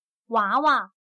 娃娃/Wáwá/Bebé, niño-a pequeño-a; muñeca.